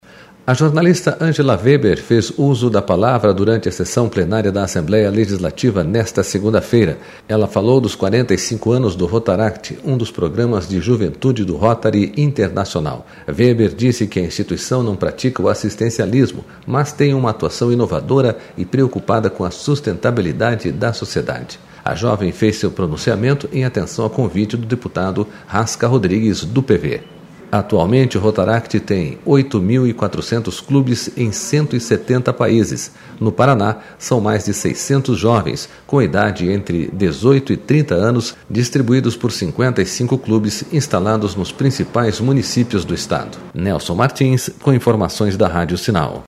A jovem fez seu pronunciamento em atenção a convite do deputado Rasca Rodrigues, do PV.//